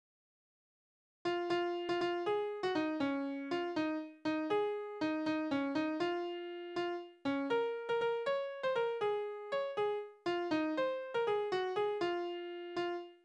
Naturlieder
Tonart: Des-Dur
Taktart: 6/8
Tonumfang: Oktave
Besetzung: vokal
Anmerkung: Vortragsbezeichnung: Ziemlich langsam. weitere Systemanmerkung: Auch C-Dur